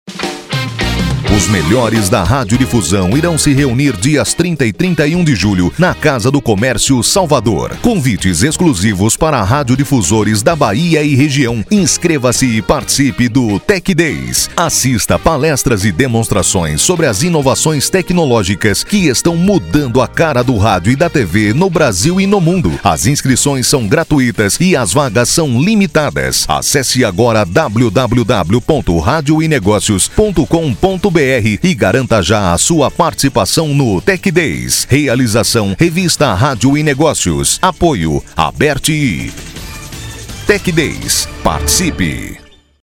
A organização do Tech Days disponibilizou um spot para as rádios associadas da Bahia ajudarem a divulgar o evento.
SPOT-TECHDAYS-BAHIA-OficialMaster.mp3